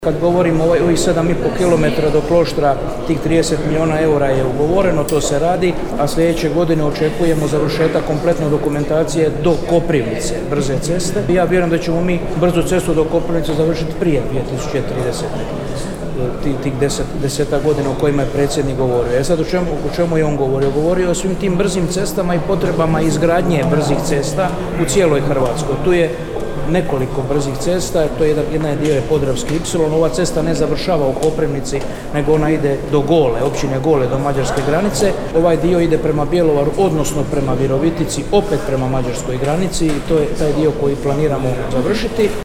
– poručio je ministar Butković u Koprivnici, napomenuvši kako vjeruje da će brzu cestu do Koprivnice završiti prije 2030. godine, otkrivši da cesta neće završiti u Koprivnici, već je plan da ide sve do granice s Mađarskom.